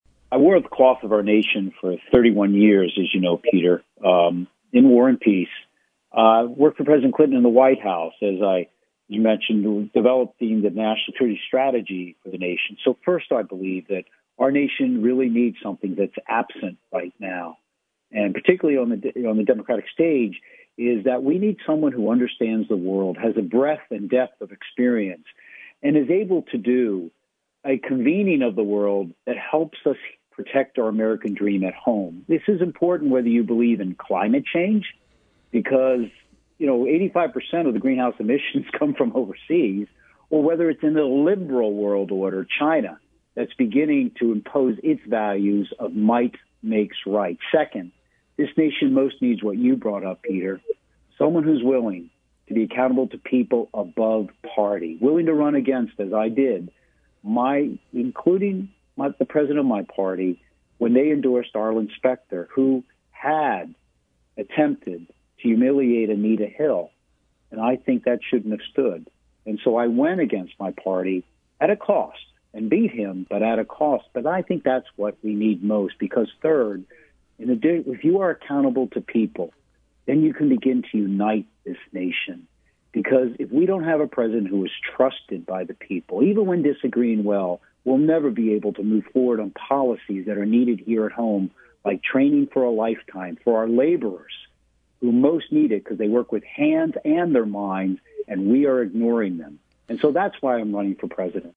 In-Depth Interview: Former Congressman, Admiral, Joe Sestak Explains Why He’s Running for President